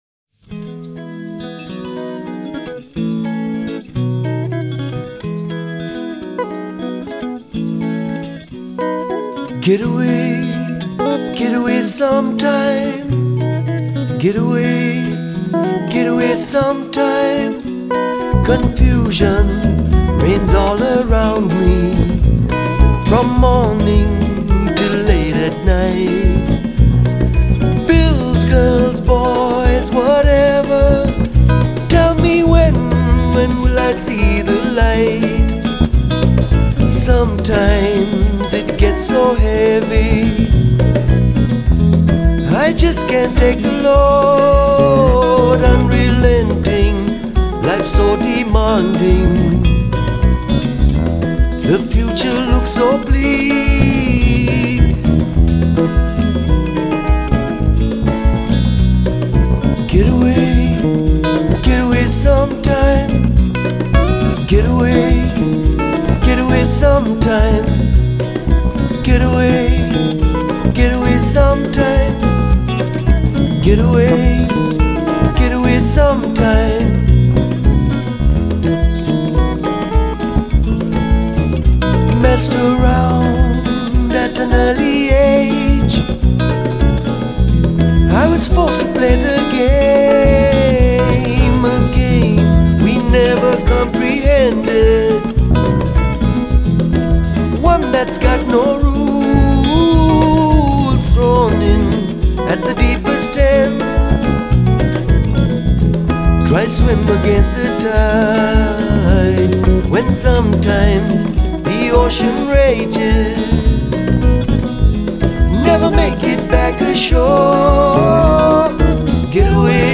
kalimba
sax, bass and balafon.